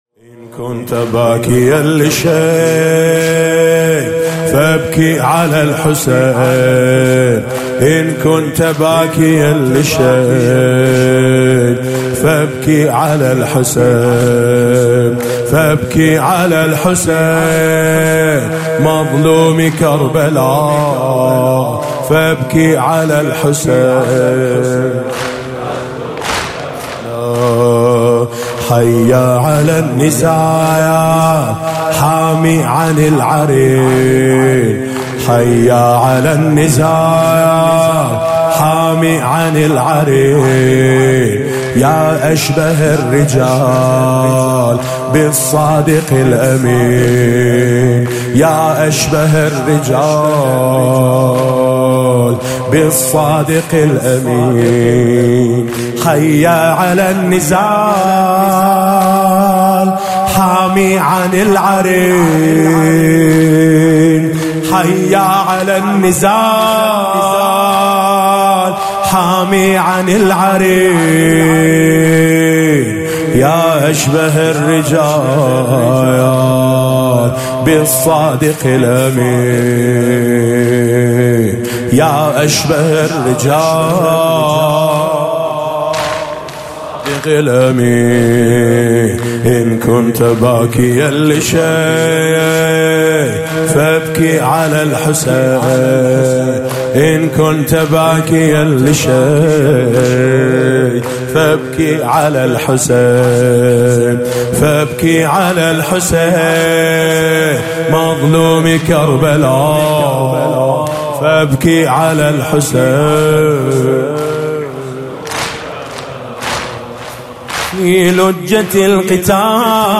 محرم 99